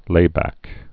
(lābăck)